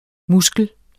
Udtale [ ˈmusgəl ]